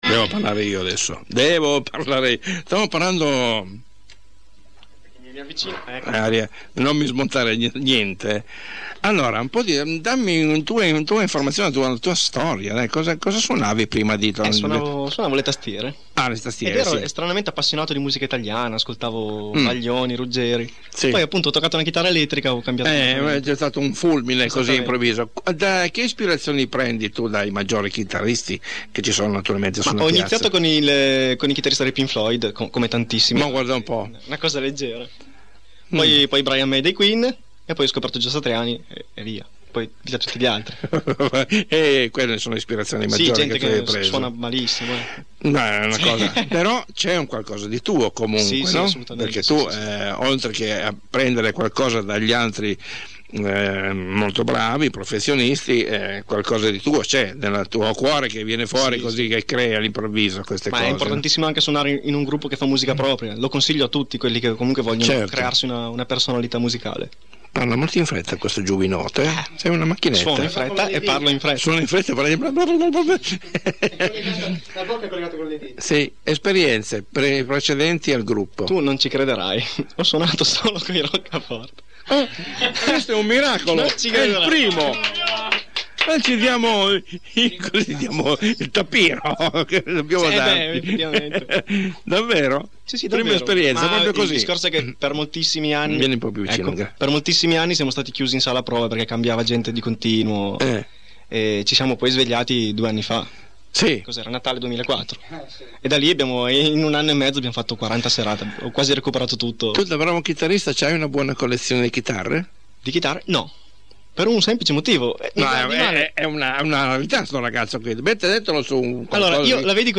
In diretta da Radio Italia 1 di Torino, Live acustico